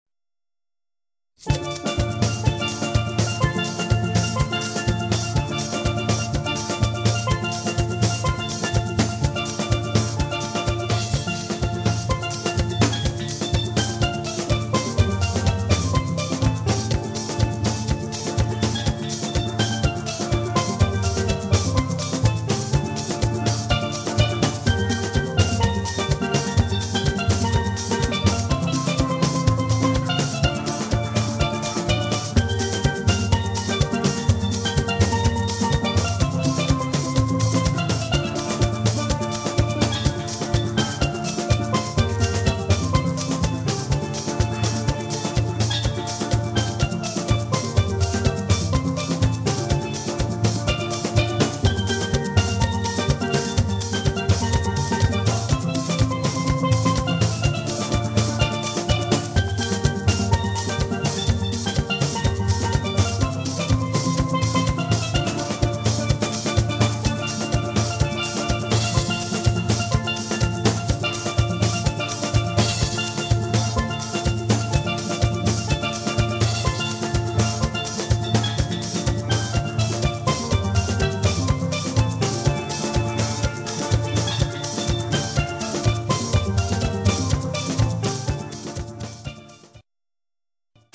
• Authentic Caribbean musicians and music
• Perfect to evoke sunny carnival ambience
• Versatile traditional steelpan ensemble